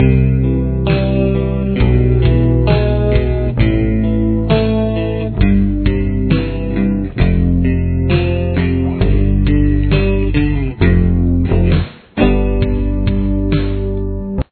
Rhythm Guitar
Much of this riff is built around arpeggiated chords.
The basic chord progression is C,E,A,C/G,F,G,F,G7.
Here’s a variation of the the basic rhythm part: